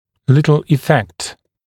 [‘lɪtl ɪ’fekt][‘литл и’фэкт]незначительный эффект, небольшое влияние